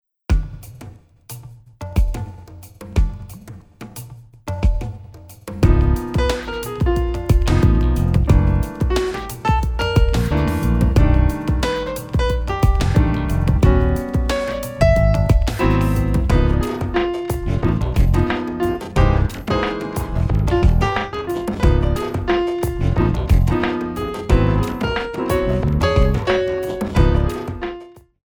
4 bar intro
up-tempo
Hip-Hop / Funk